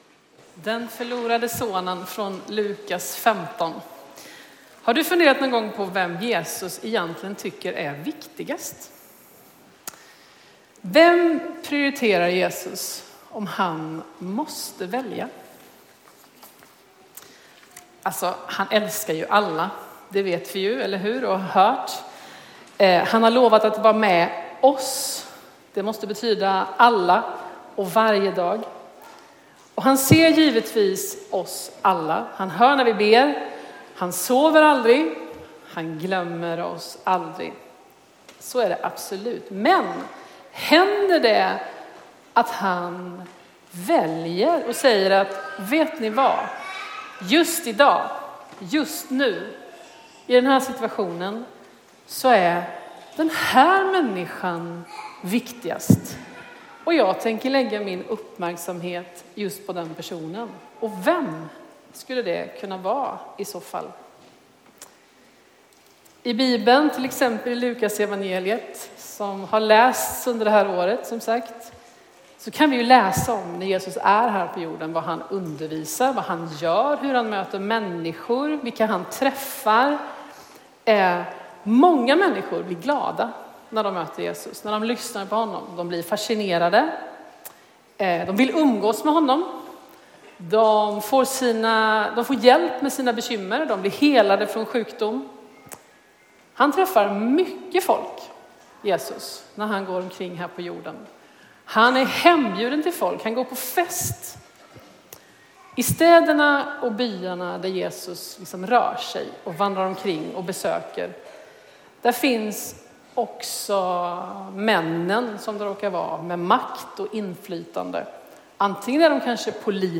Här publiceras inspelade predikningar från gudstjänster i Ryttargårdskyrkan, Linköping.